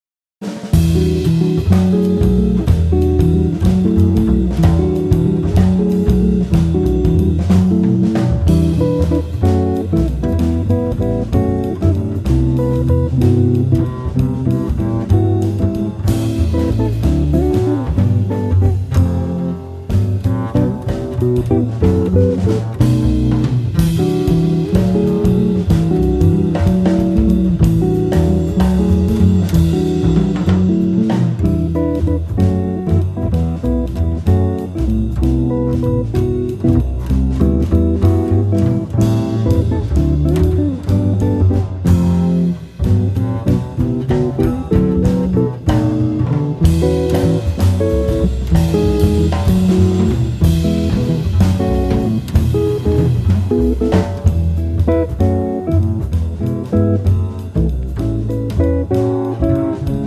batteria
contrabbasso
chitarra elettrica